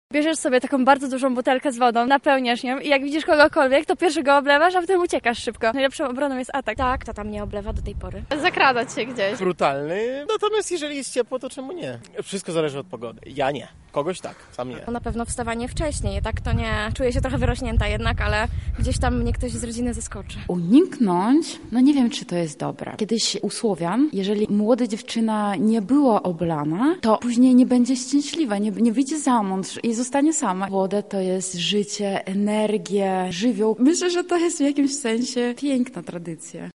Zapytaliśmy się lublinian o przemyślenia dotyczące oblewania i bycia oblewanym,
SONDA